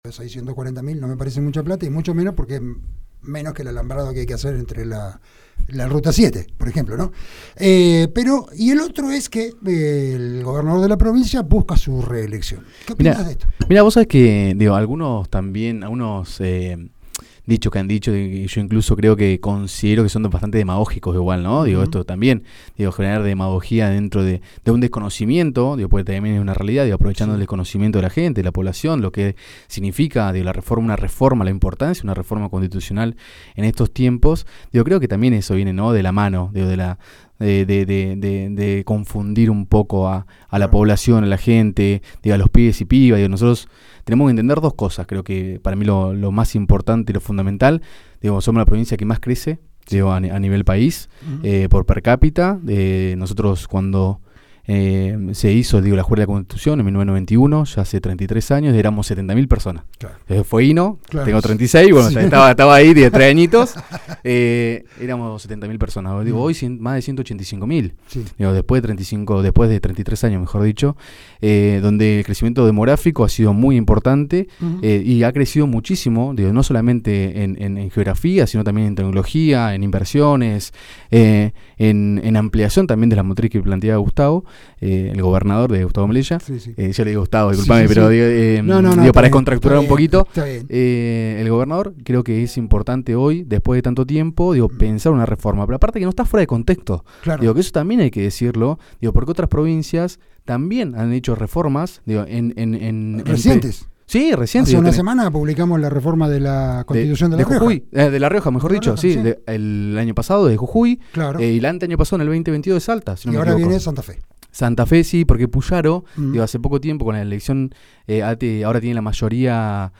en diálogo con Resumen Económico, en Radio Provincia